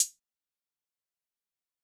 Closed Hats
Metro Hats [808 Hat].wav